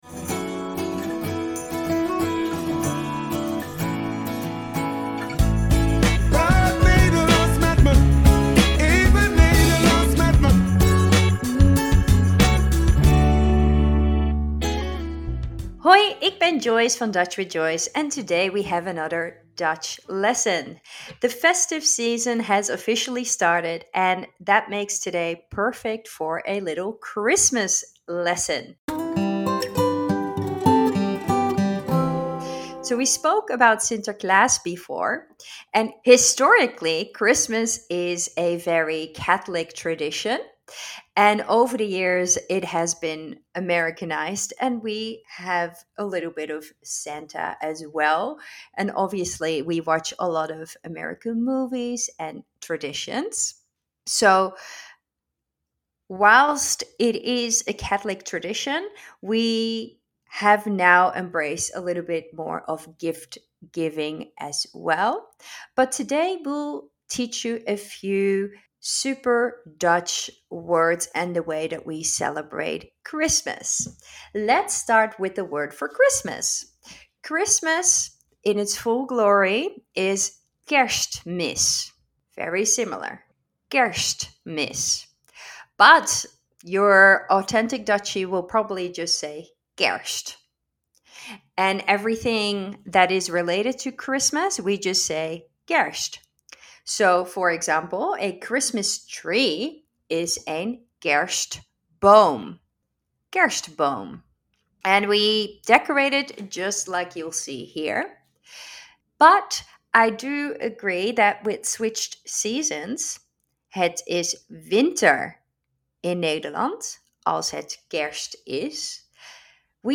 In deze podcastserie leer je wekelijks in een paar minuten enkele Nederlandse woordjes en uitspraken.